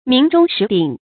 鸣钟食鼎 míng zhōng shí dǐng
鸣钟食鼎发音